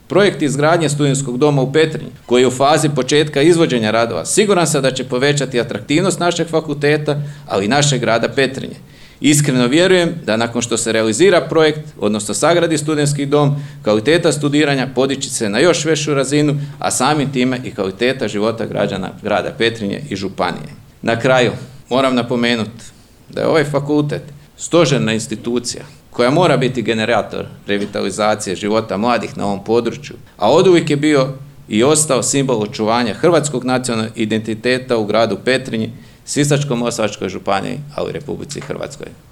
U utorak, 04. ožujka 2025. godine, u Petrinji je svečano otvorena novoobnovljena zgrada Učiteljskog fakulteta – Odsjek u Petrinji.